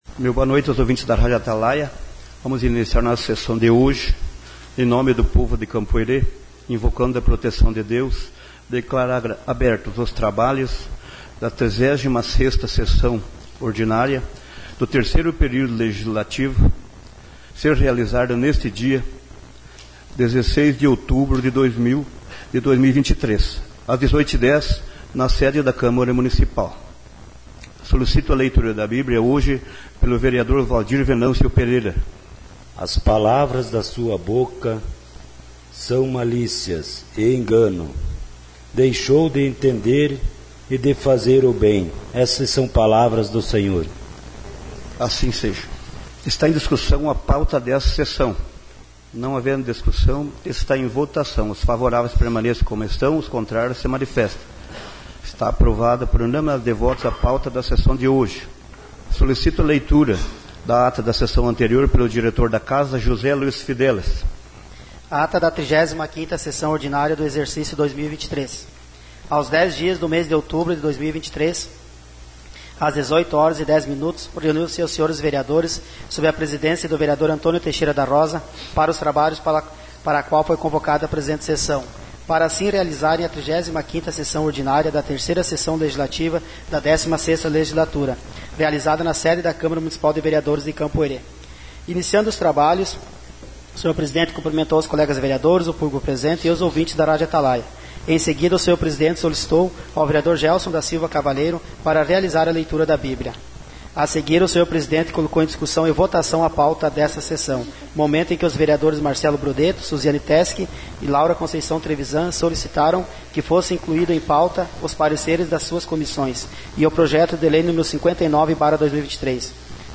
Sessão Ordinária 16/10